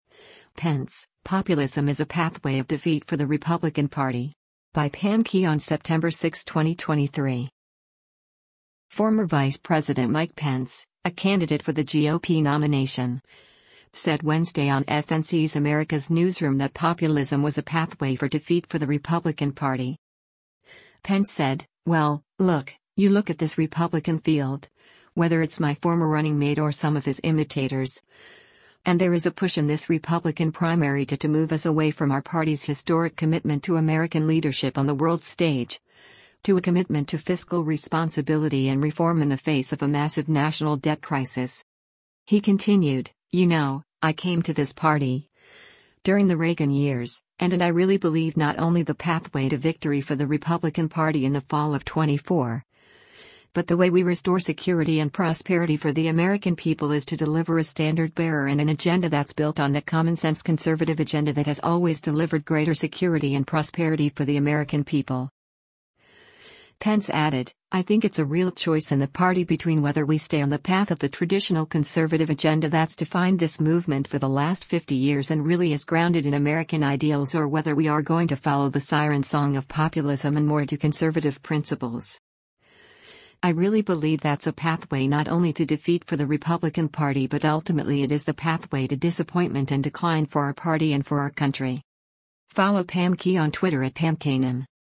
Former Vice President Mike Pence, a candidate for the GOP nomination, said Wednesday on FNC’s “America’s Newsroom” that populism was a pathway for “defeat for the Republican Party.”